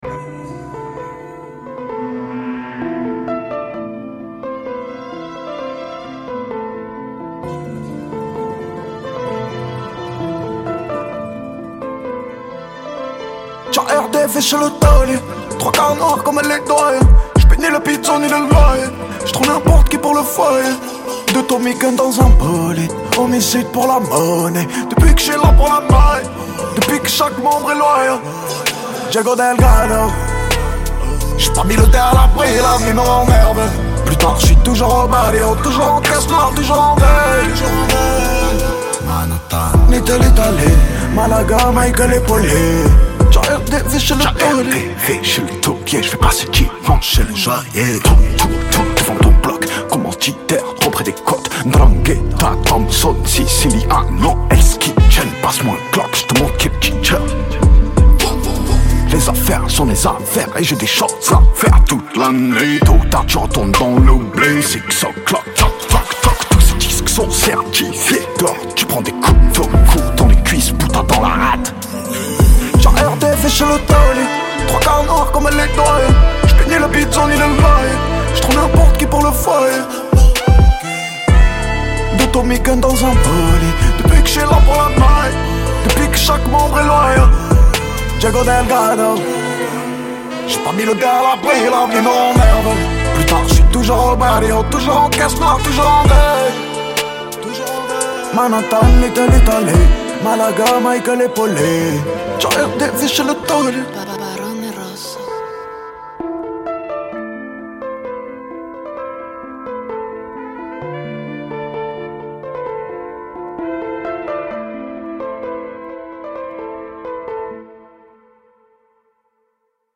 french rap, pop urbaine Télécharger